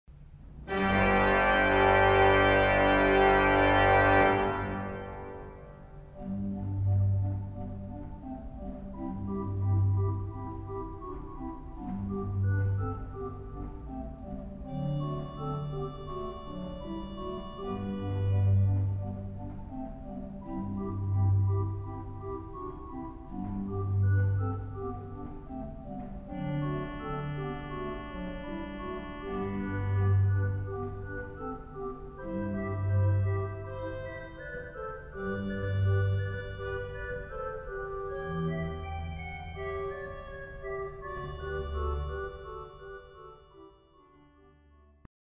Grande pièce d’esprit symphonique. Le Nazard du Positif simule le pizzicato des cordes et soutient le motif principal sur la lointaine Trompette d’Echo ; Grand chœur avec plusieurs crescendos par changements de claviers et adjonction de jeux d’anches ; conclusion à l’Echo.
Positif : Bourdon 8, 2e Flûte 8, Nazard
G.O. : Cornet, 1ère et 2e Trompette, Clairon
Récit : Bourdon 8, Cornet IV